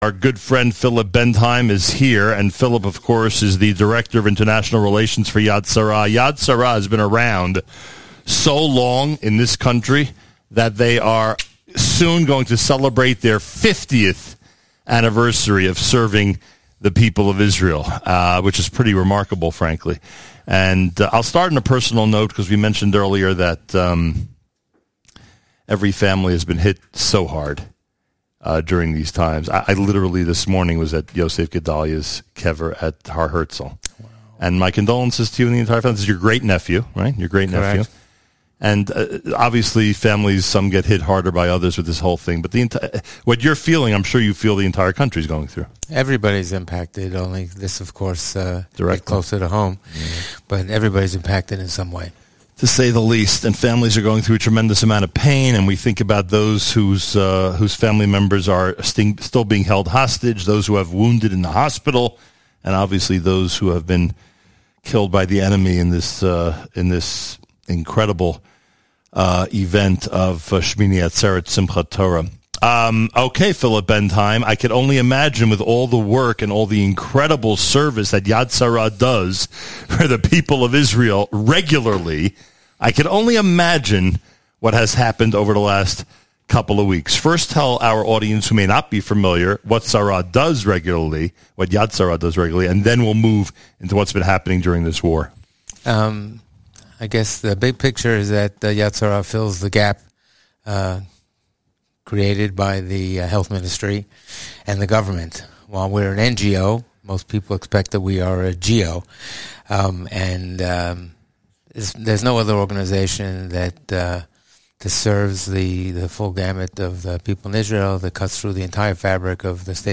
Live in Jerusalem